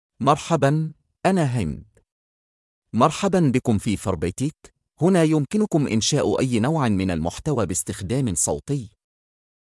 MaleArabic (Saudi Arabia)
Hamed is a male AI voice for Arabic (Saudi Arabia).
Voice sample
Male
Hamed delivers clear pronunciation with authentic Saudi Arabia Arabic intonation, making your content sound professionally produced.